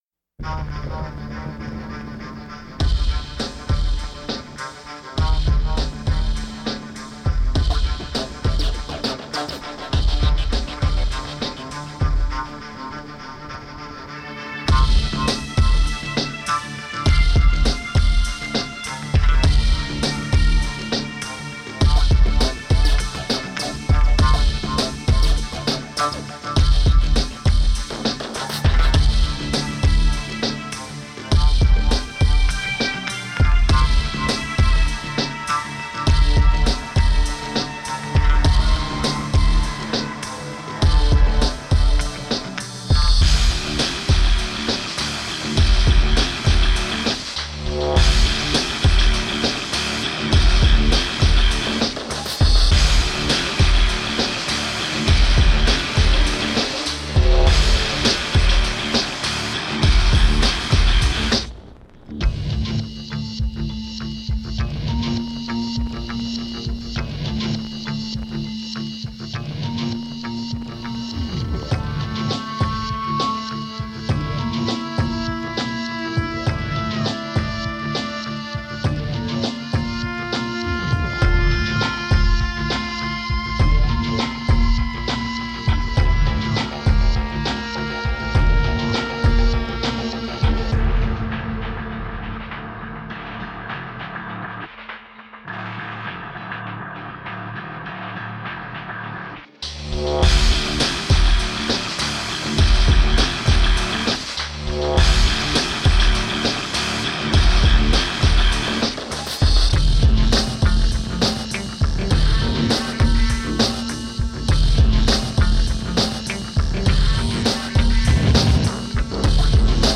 Музыкальная композиция из саундтрека фильма